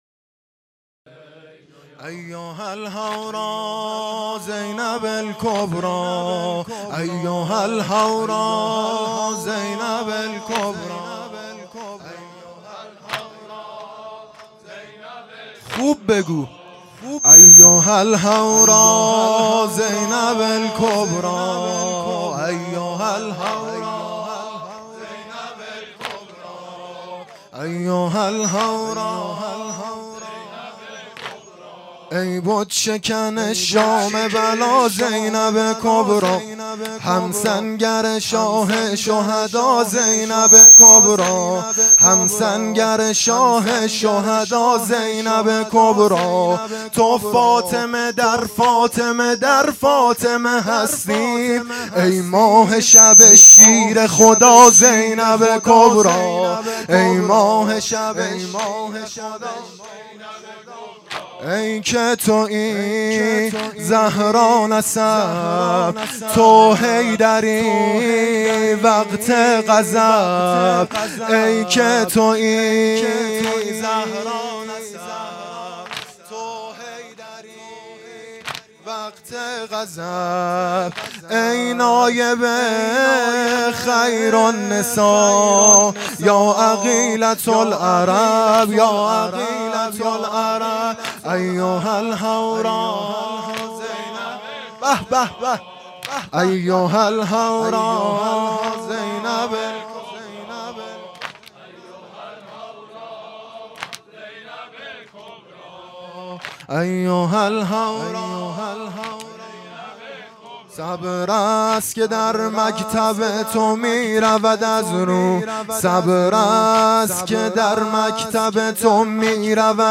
شب دوم محرم 1440